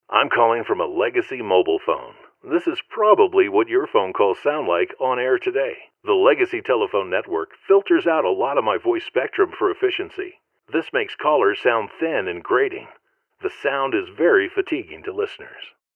Legacy-mobile-phone-recording.wav